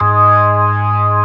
55o-org08-G#2.aif